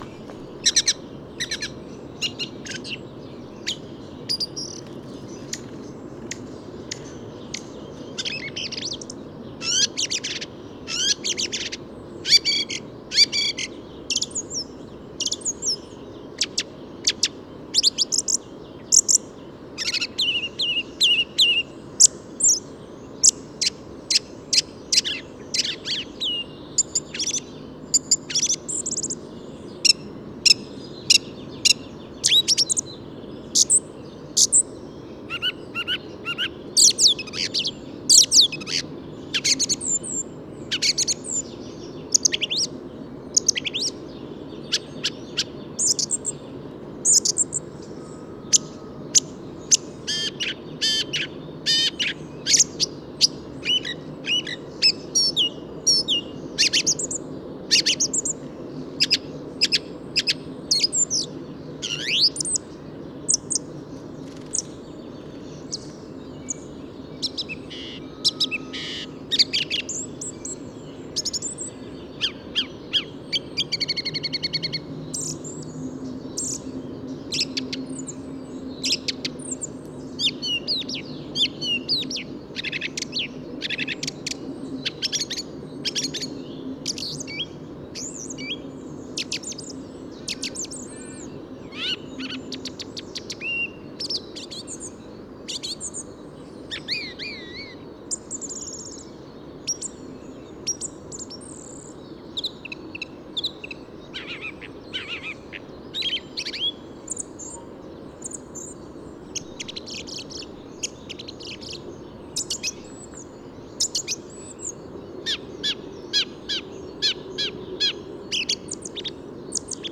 Generally it´s crystallised song seems to be simple structured, but it´s
plastic song is much more coherent, with less gaps between the verses and
100323, Song Trush Turdus philomelos, plastic song, Papitzer Lachen, Germany
10_song_trush.mp3